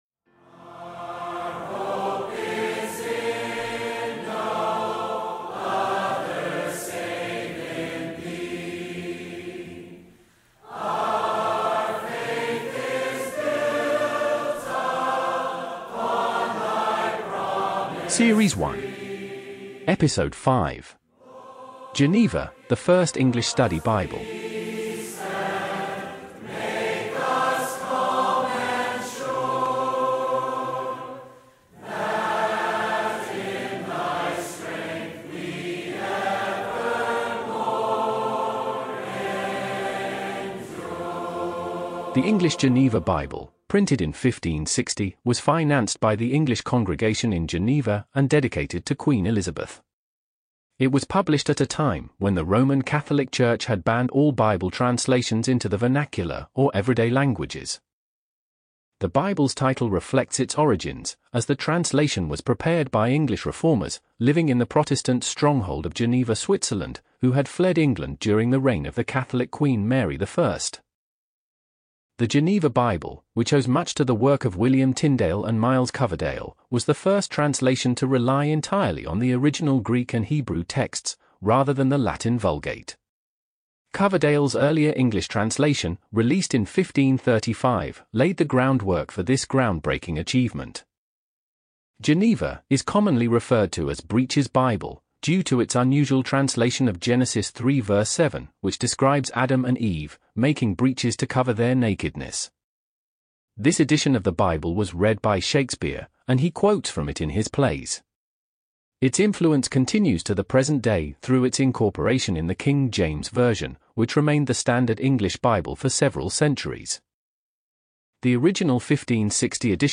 The Music is I Greet Thee, Who My Sure Redeemer Art, Sung by the Capitol Hill Baptist Church, Washington, D.C. Author: Anonymous.